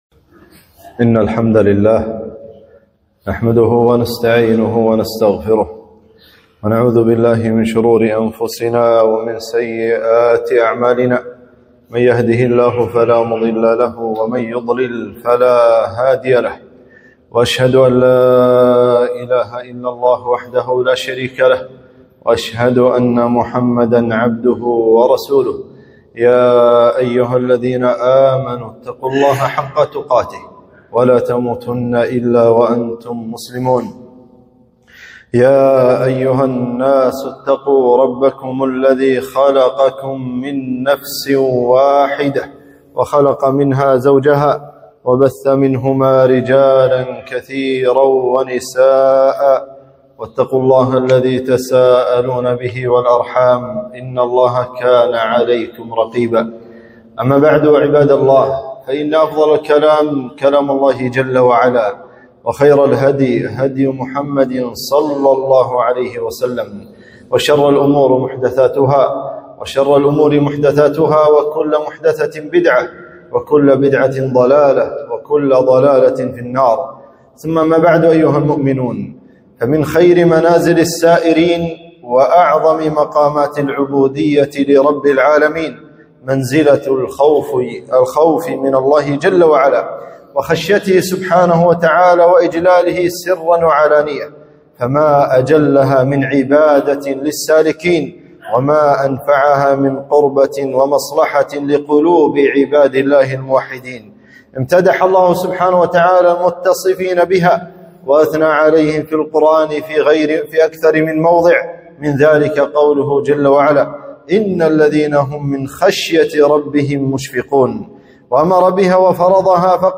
خطبة - خشية الله